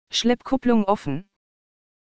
Diesen sind wie oben beschrieben ebenfalls mit dem online tool